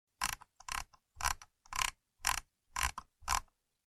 Mouse Clicks And Scrolling